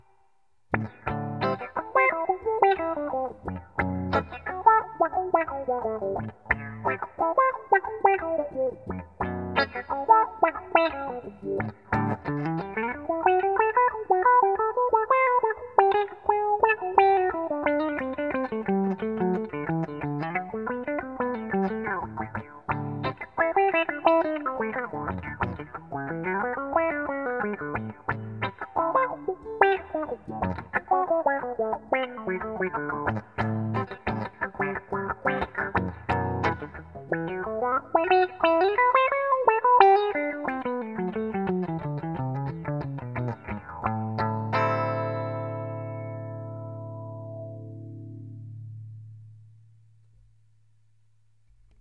Short attack, max intensity:
fast-attack-_high-resonance_demo.mp3